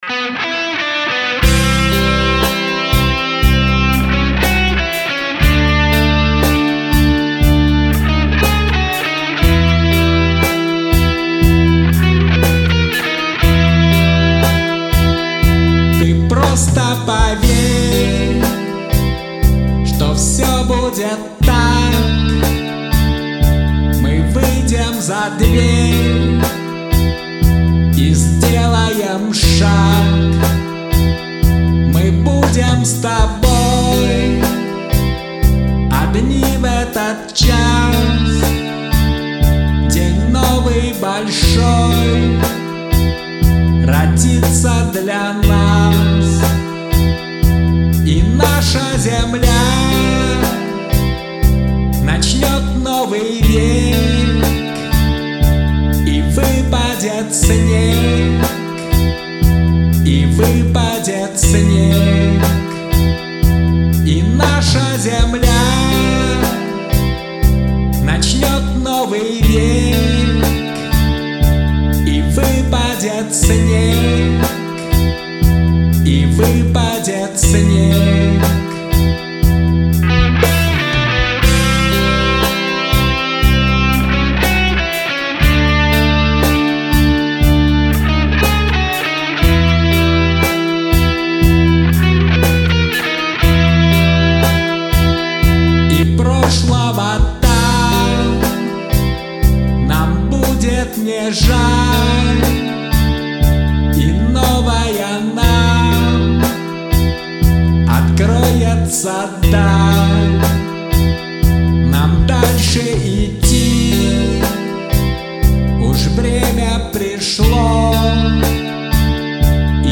(2018, Рок)